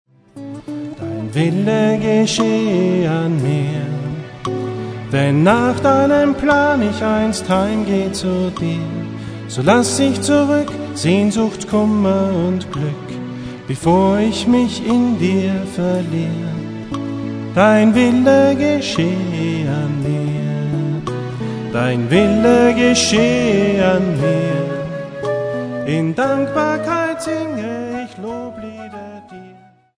Lobpreis & Anbetung